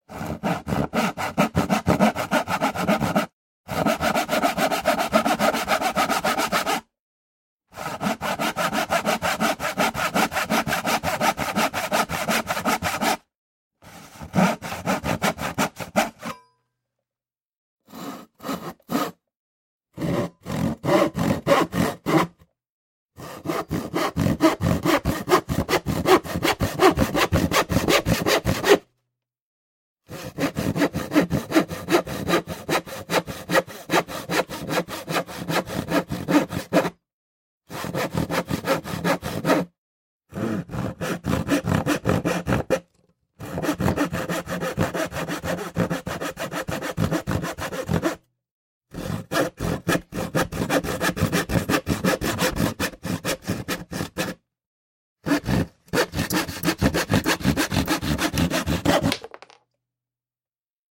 На этой странице собраны звуки ножовки в разных вариациях: от плавных движений по дереву до резких рывков при работе с металлом.
Звук резкой пилы при распиливании бруска